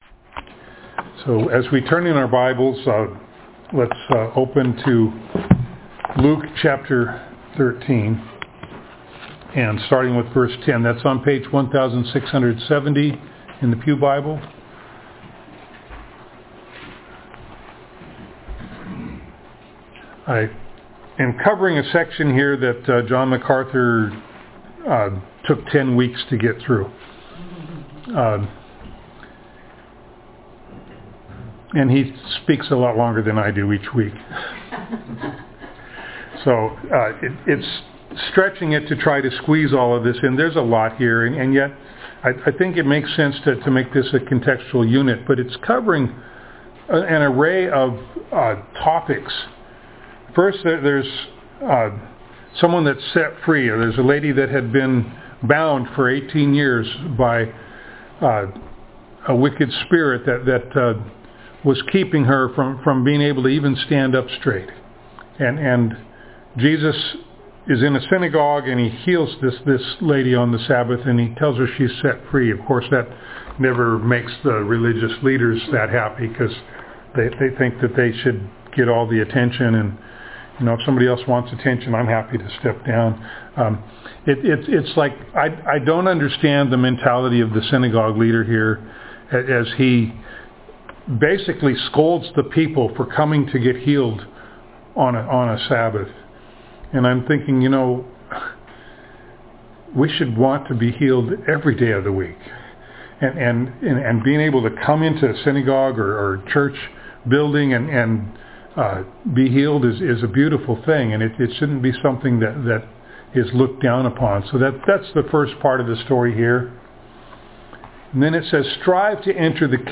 Passage: Luke 13:10-35 Service Type: Sunday Morning